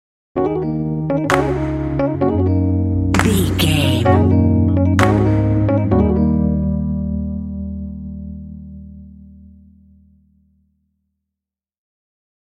Aeolian/Minor
E♭
Slow
cool
funky
bass guitar
electric organ
70s
hip hop